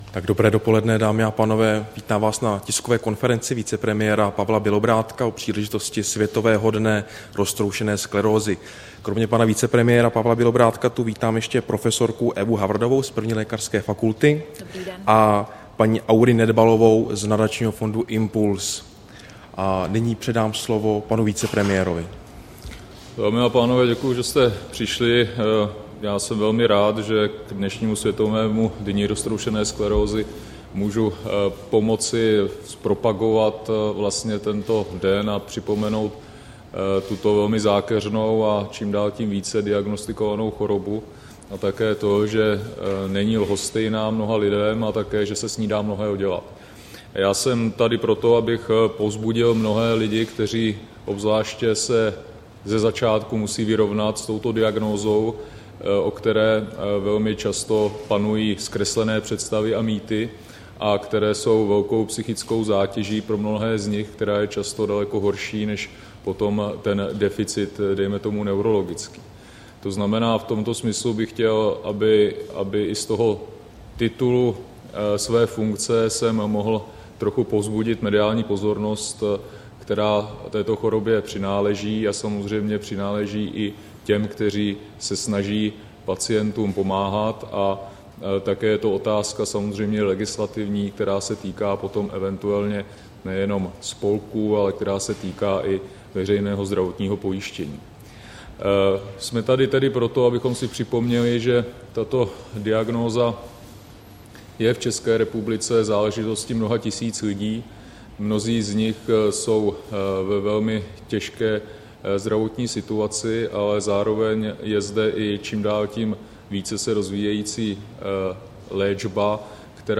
Tisková konference vicepremiéra Bělobrádka ke Světovému dni roztroušené sklerózy, 28. května 2014